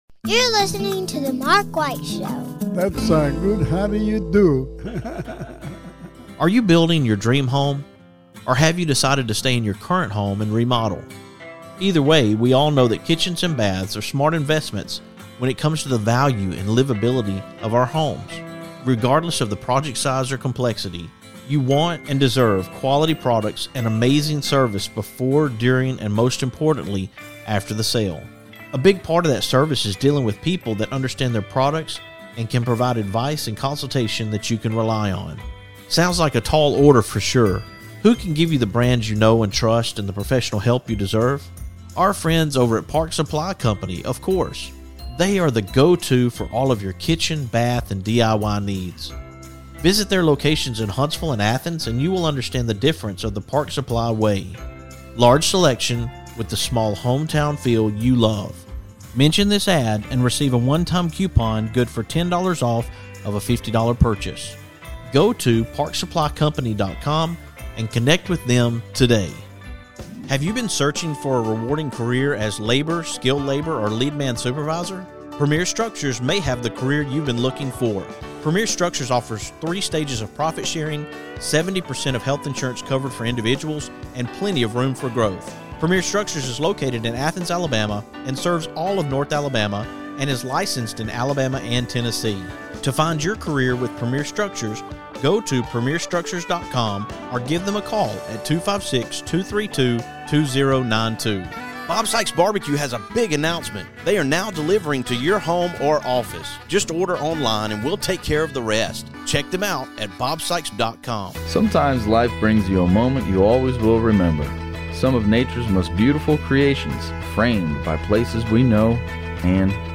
a chat from the new studio!